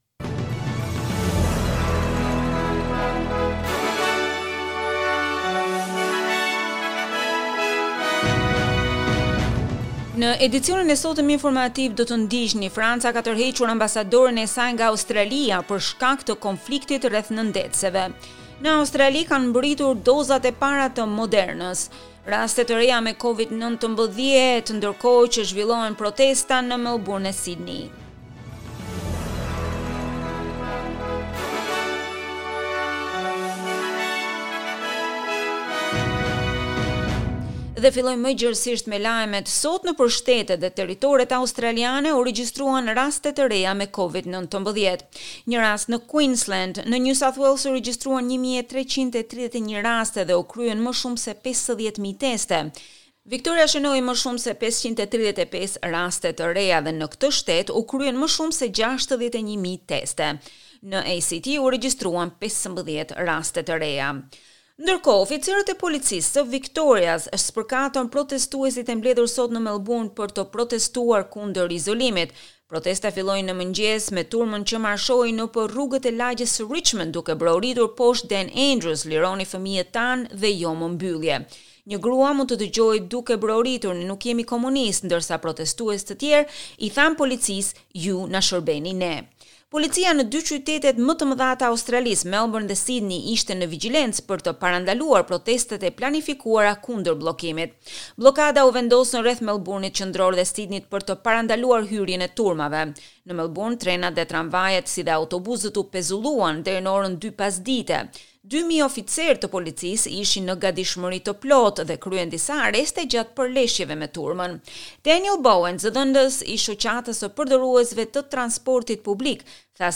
SBS News Bulletin in Albanian- 18 September 2021